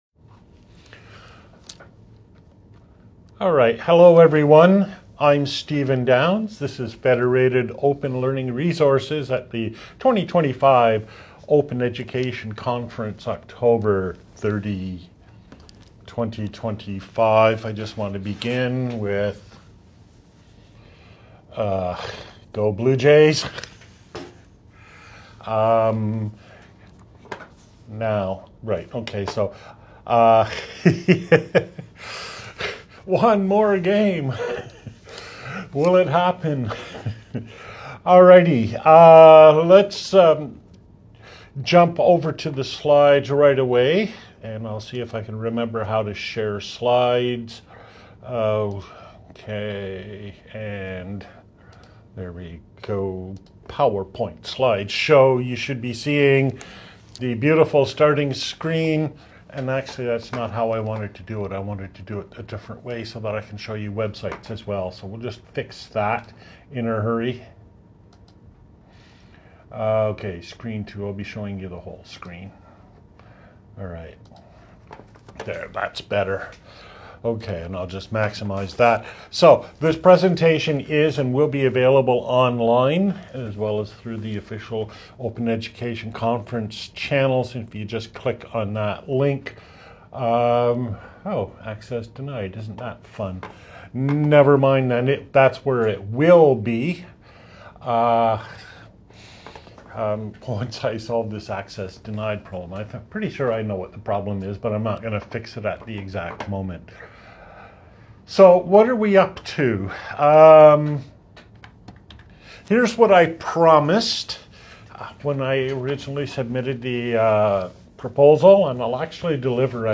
(Old style) [ Slides ] [ PDF ] [ Audio ] [ Video ] (New Style) [ PDF ] [ PPTx ] [ Audio ] [] 2025 Open Education Conference, OpenEd, Online, to Denver, Colorado, Seminar, Oct 30, 2025.